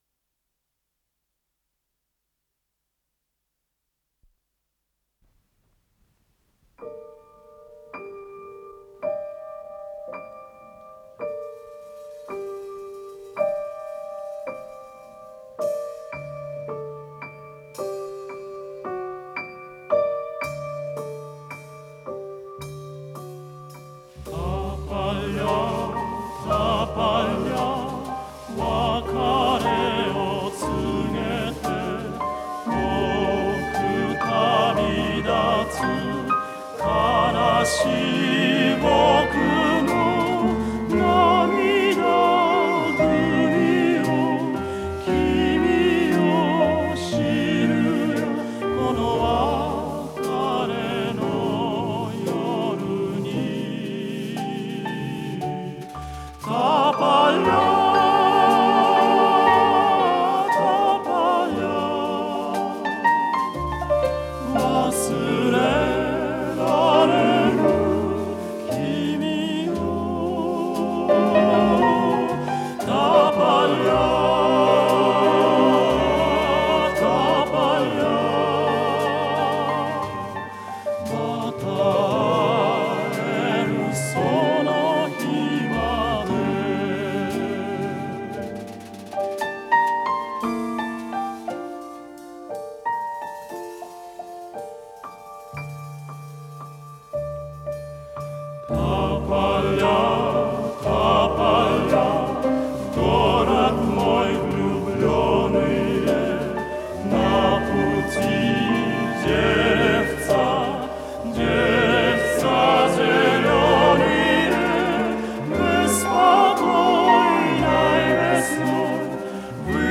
с профессиональной магнитной ленты